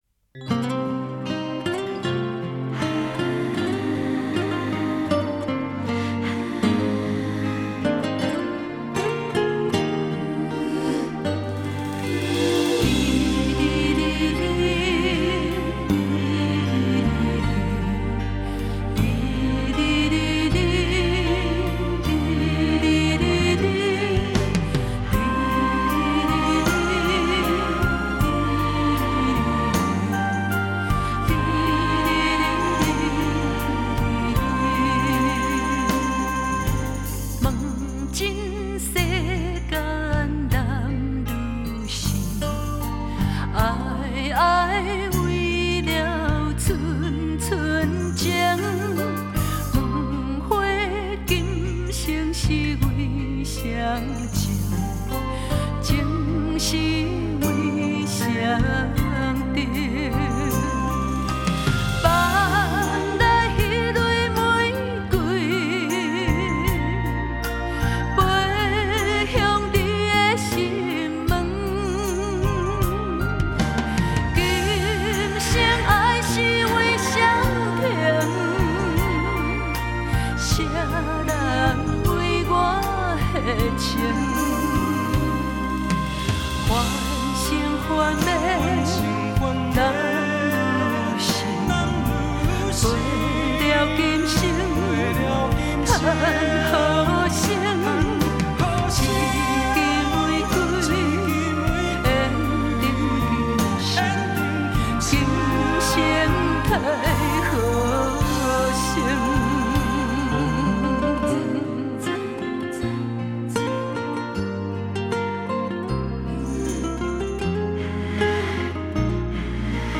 收录多首最新超夯经典原声原影台语流行金曲!
动人的情歌，让人更感窝心
金曲歌王歌后联手出击